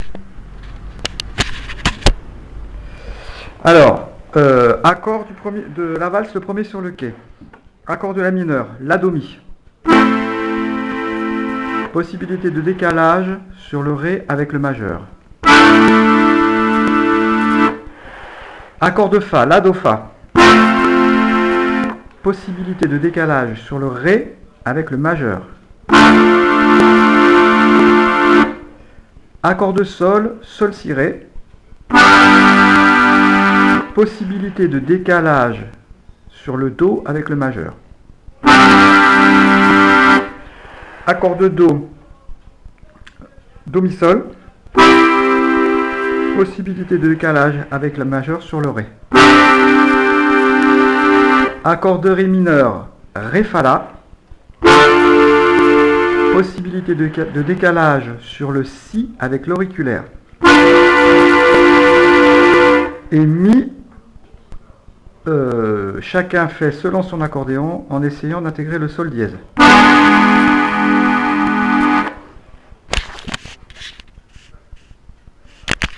l'atelier d'accordéon diatonique
les accords et les décalages possibles:
1er quai accords MD